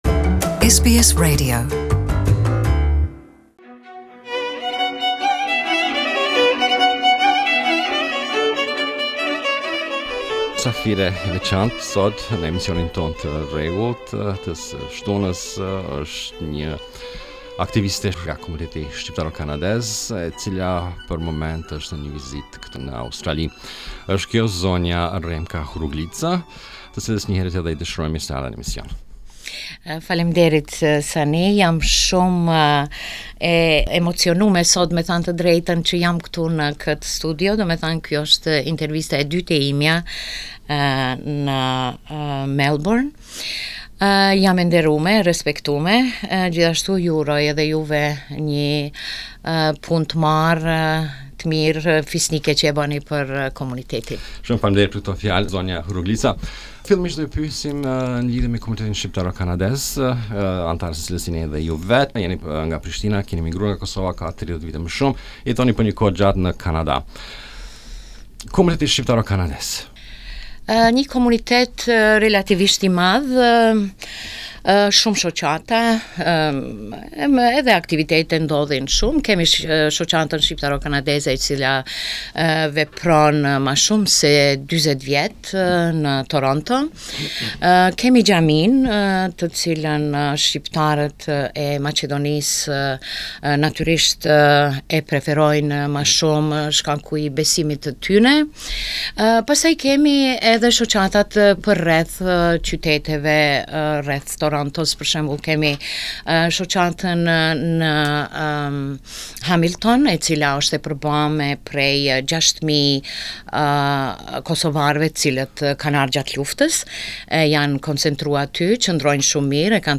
Ne e ftuam ate ne studio dhe i morren nje interviste.